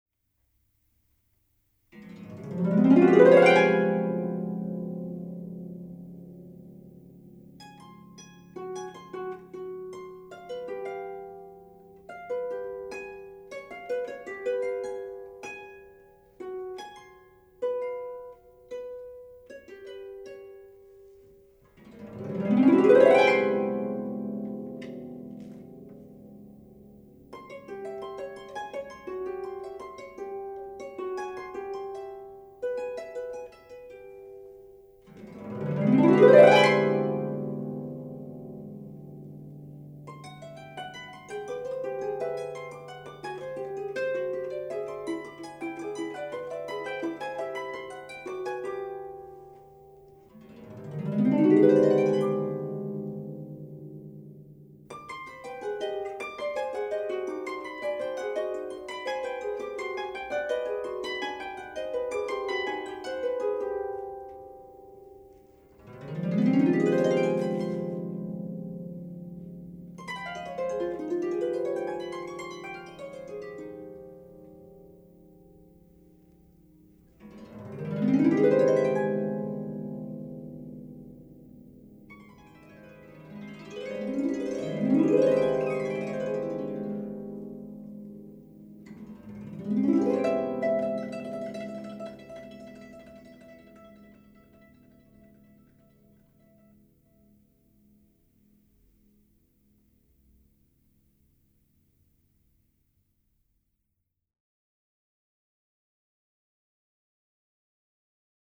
Musica per balletto
Per voce recitante e canto
flauto e ottavino
arpa
oboe
violino
pianoforte e clavicembalo
chitarra e percussioni